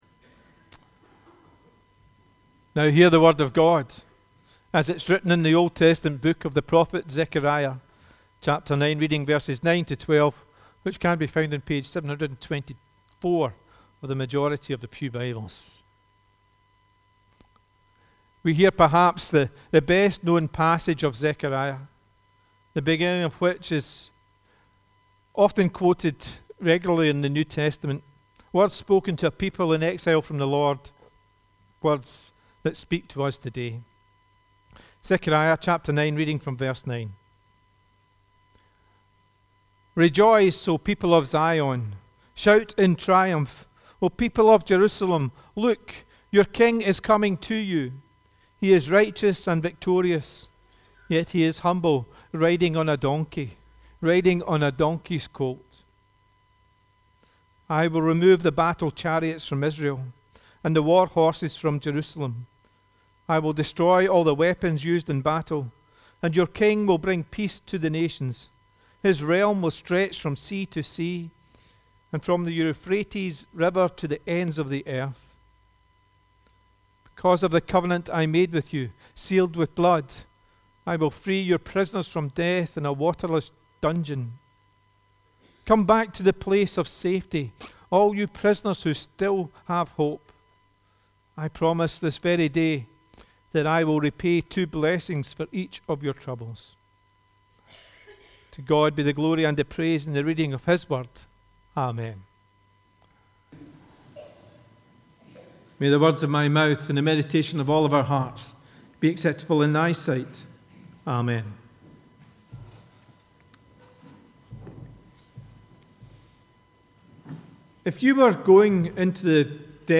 The reading prior to the sermon is Zechariah 9: 9-12